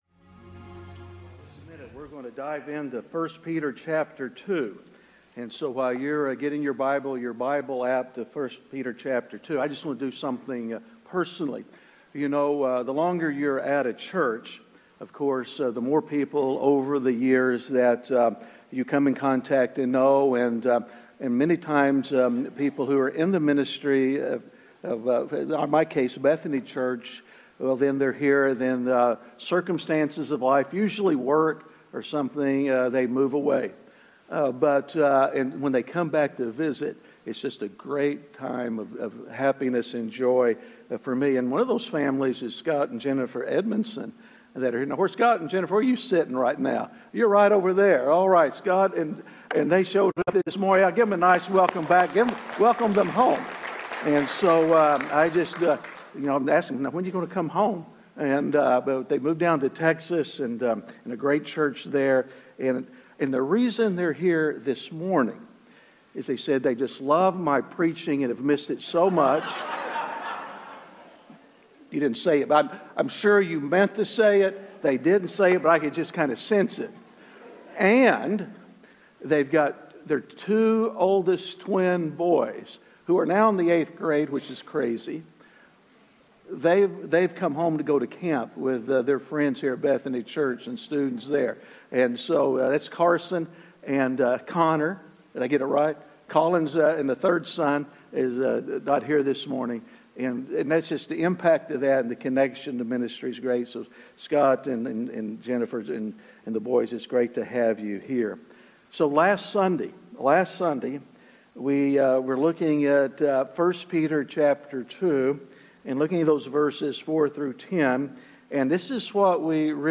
Missed a Sunday? You can go back and catch up on any of the sermons you missed.